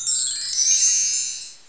magic(1).mp3